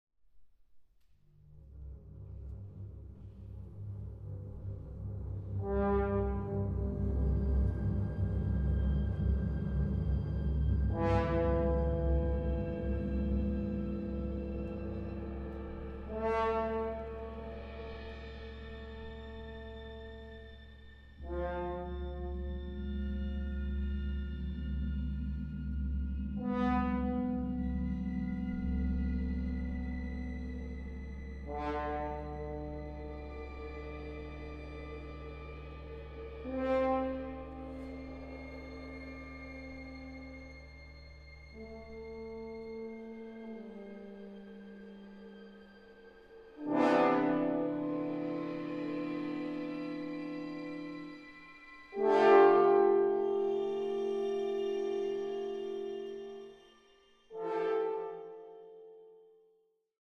Recording: Katharina-Saal, Stadthalle Zerbst, 2025
für großes Orchester